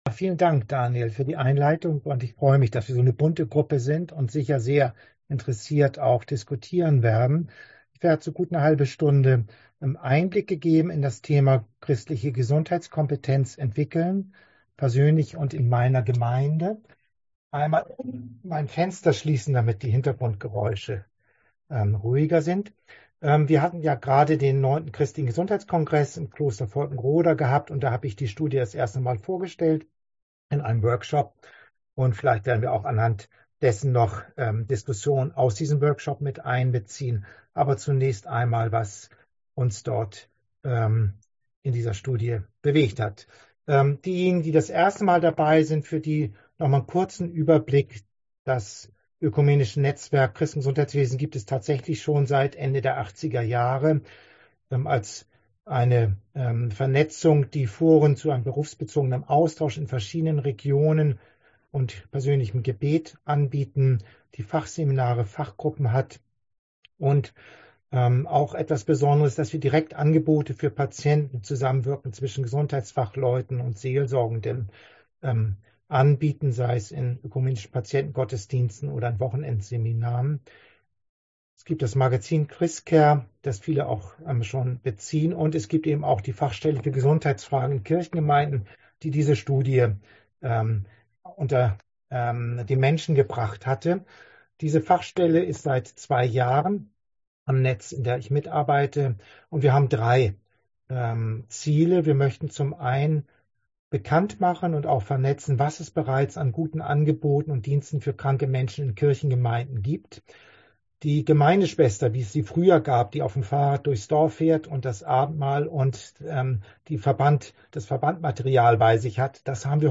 Digitales Kurzseminar